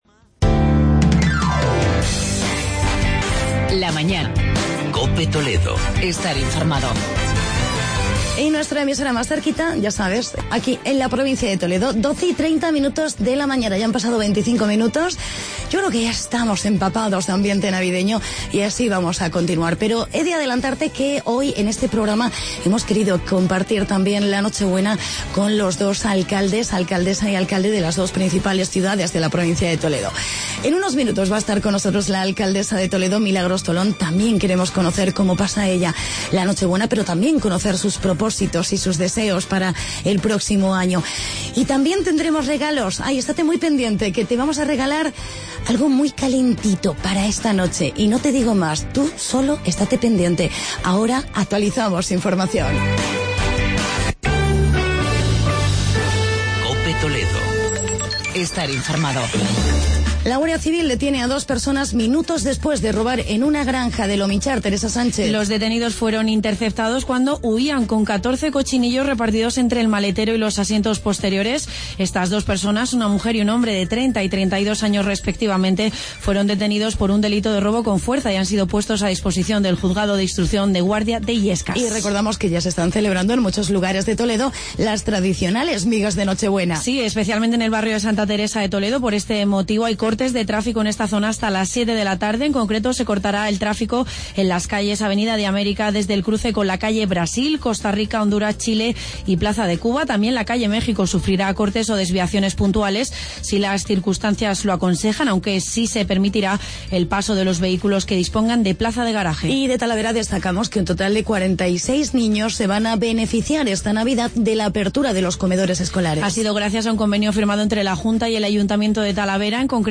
Entrevista con la alcaldesa de Toledo, Milagros Tolón y concurso Cheap Multimarca.